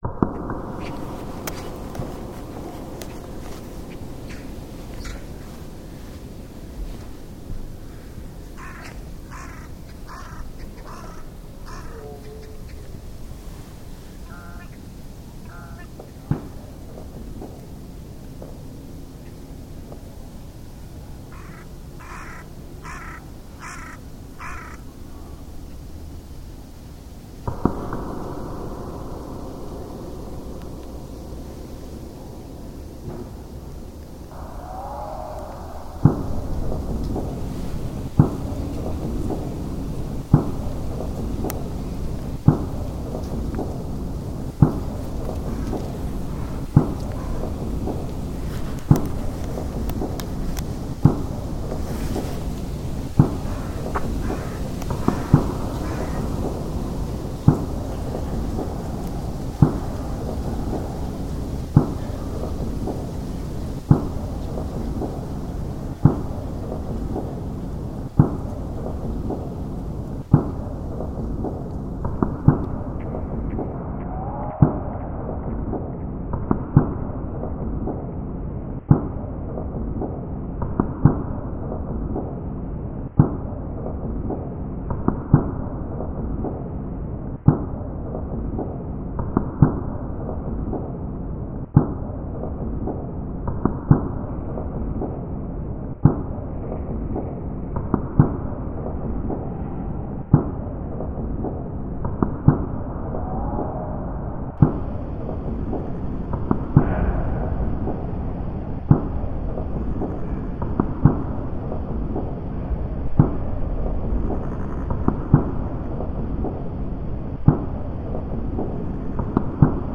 Harbin Tiger Park reimagined